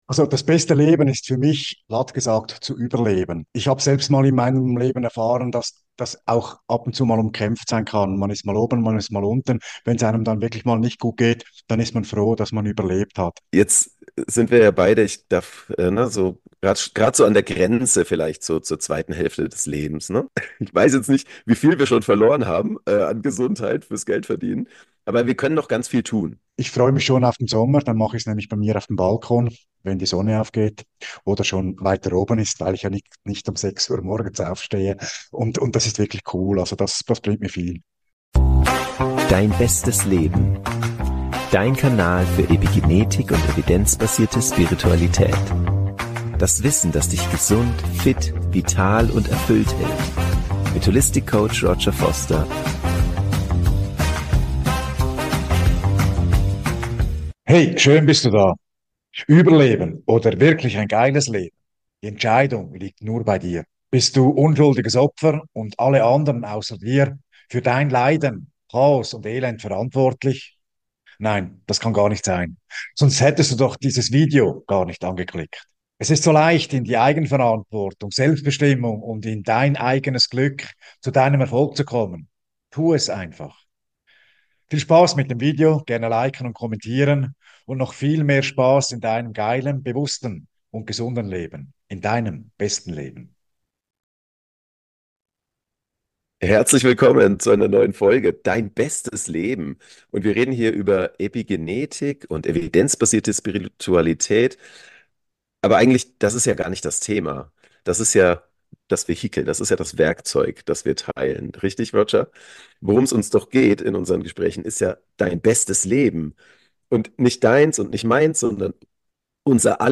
Spitzengespräch: So gelingt Leben - das beste Leben ~ Dein bestes Leben: Evidenzbasierte Spiritualität und Epigenetik Podcast